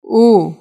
u (put)